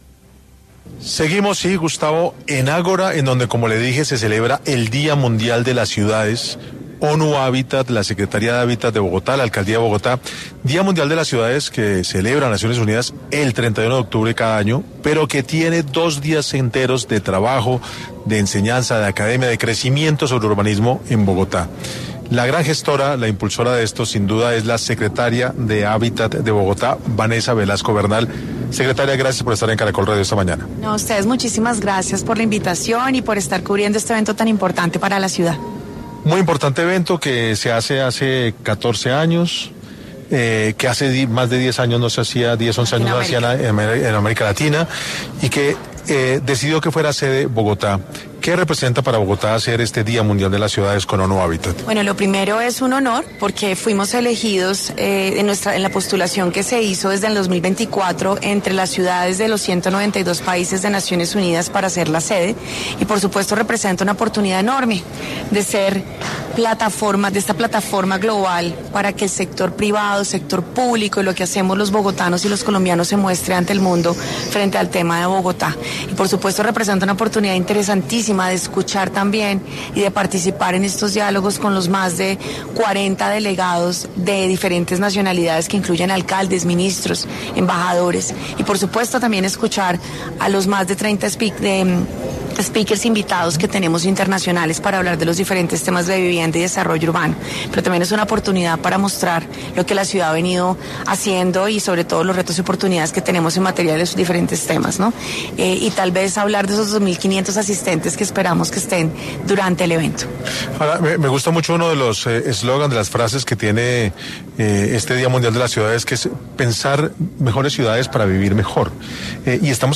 Vanessa Velasco Bernal, secretaria de Hábitat, habló en 6 AM de Caracol Radio